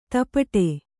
♪ tapaṭe